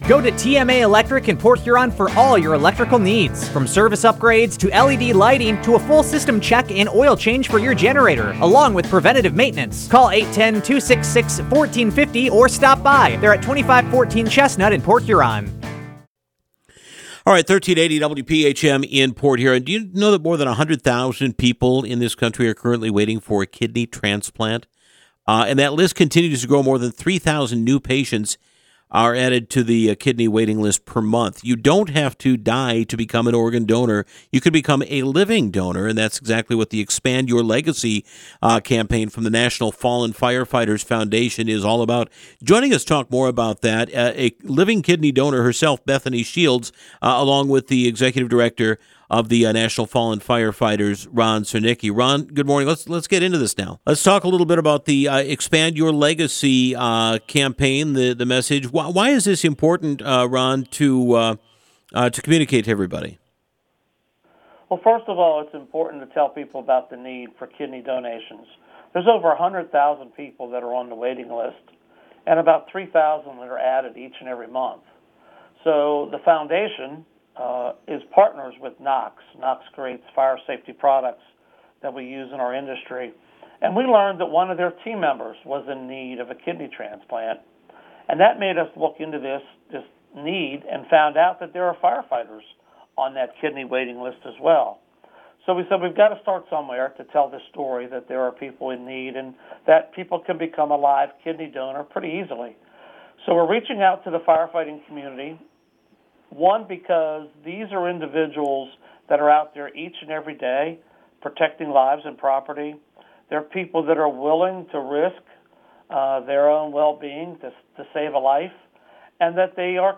912-kidney-donor-intvw.mp3